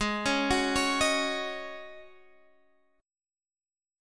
Ab7sus4 Chord